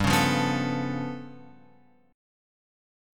G 9th Flat 5th
G9b5 chord {3 4 3 4 x 5} chord